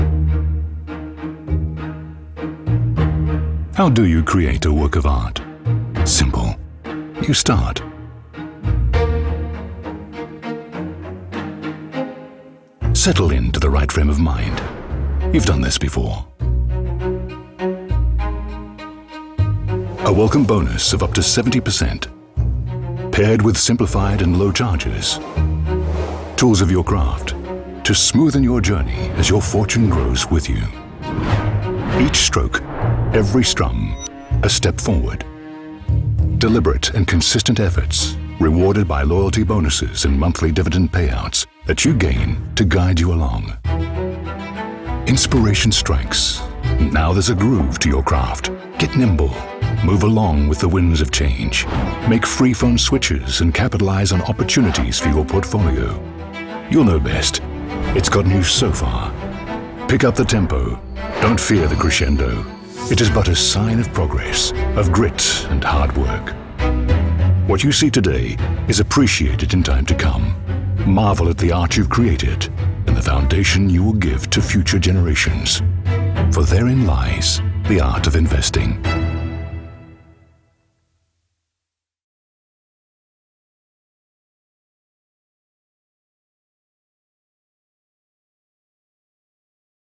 Not American but not quite British either, I have a neutral accent that has been well received in continental Europe precisely because it is neither.
English - Transatlantic
Middle Aged